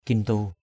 /kin-tu:/ (t.) cao quý, quý phái.